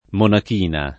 monak&na] s. f. — lo stesso e più pop. che monacella e monachella come dim. di monaca; inoltre, nome pop. di vari uccelli; e il pl. le monachine (che vanno a letto) [le monak&ne (ke vv#nno a ll$tto)], nel linguaggio infantile, le ultime faville di carta bruciata che si vanno spegnendo